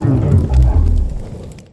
Grito